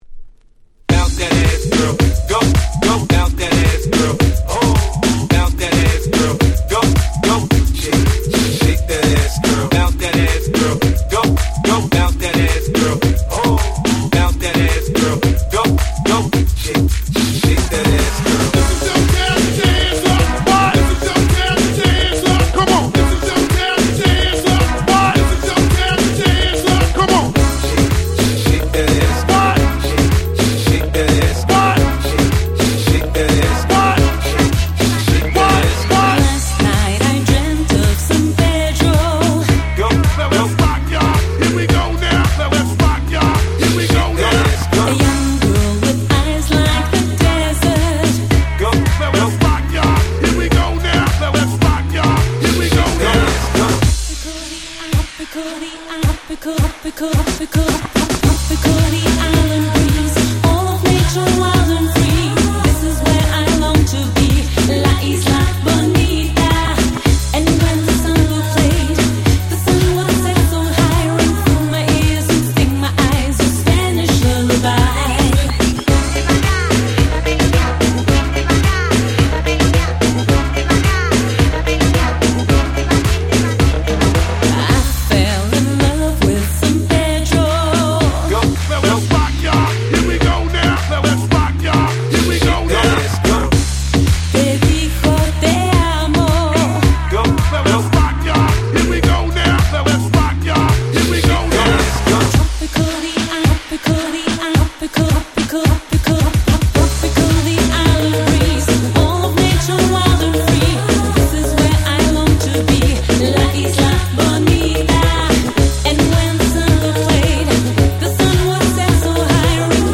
06' Nice Reggaeton Cover !!
アゲアゲなので良し！(笑)
Reggaeton
キャッチー系